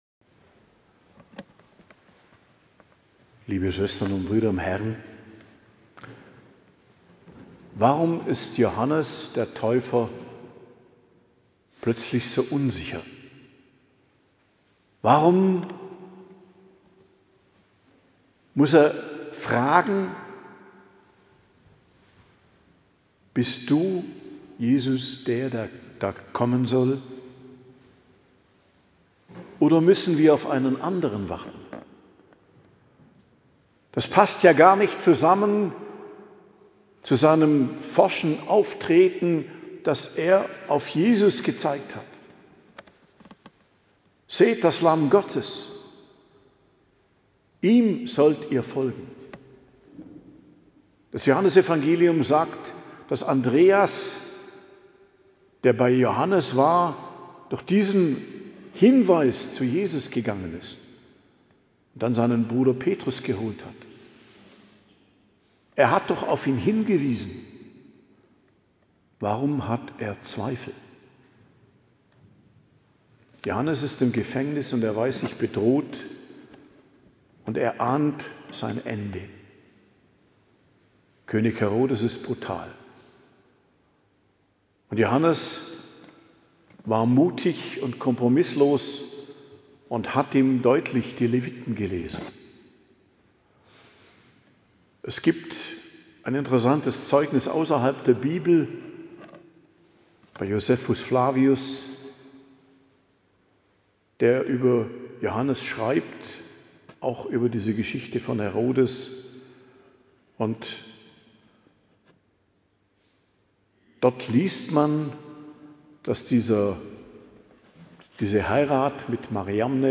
Predigt zum 3. Adventssonntag, 14.12.2025 ~ Geistliches Zentrum Kloster Heiligkreuztal Podcast